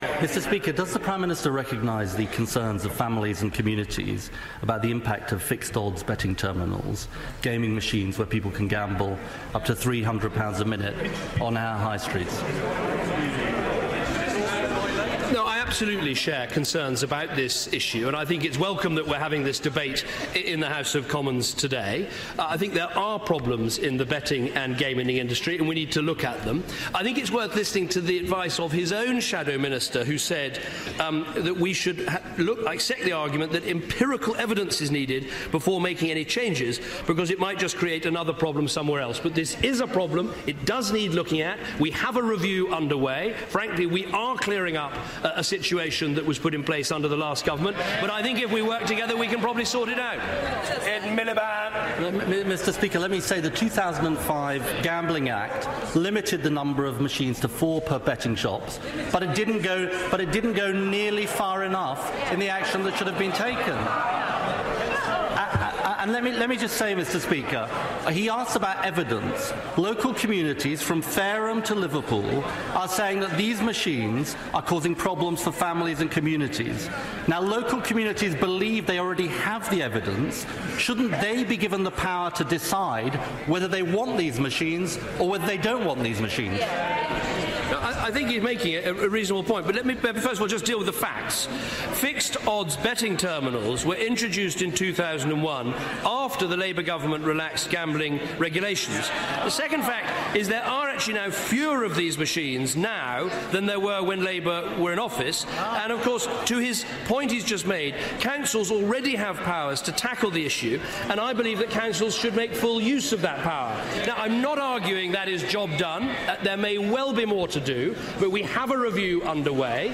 PMQs, 8 January 2014